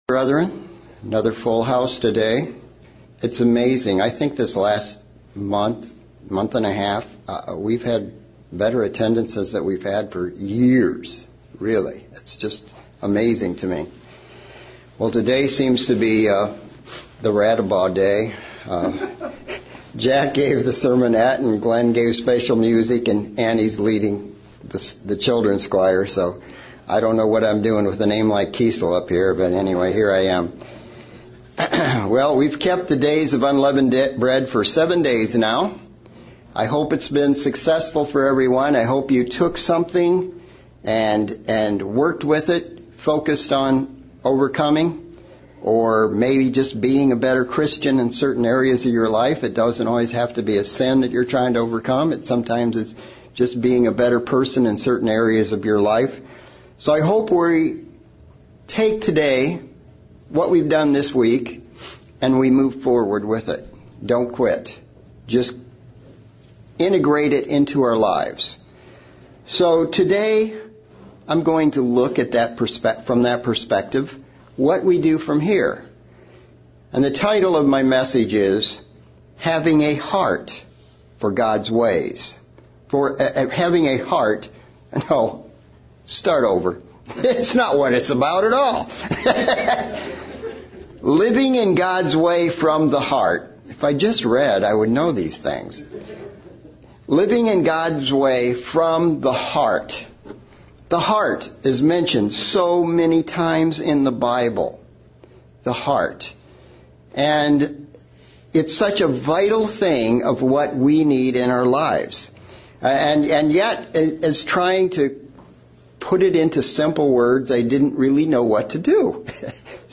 LDUB AM sermon looking at how having Christ in us the true Unleavened Bread keeps his laws and his ways on our minds and hearts. This enables us to truly live God's way in the heart as he would have us do.